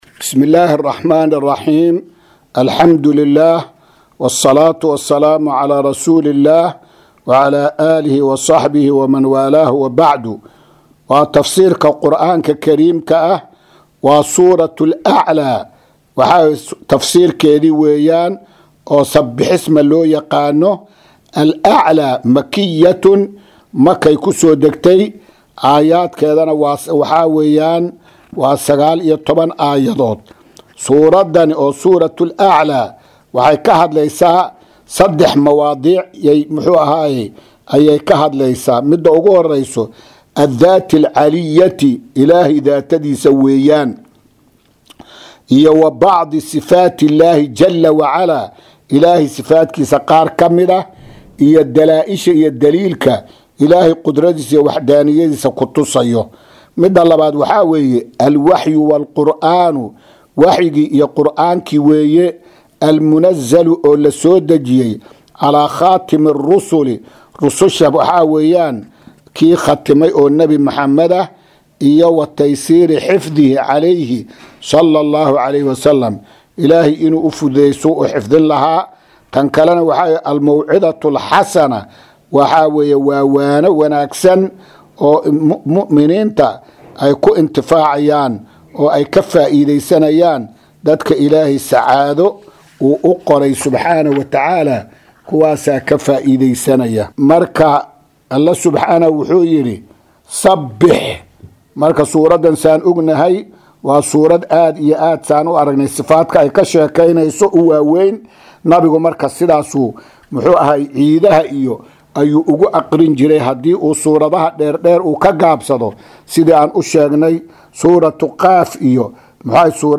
Casharka-284aad-ee-Tafsiirka.mp3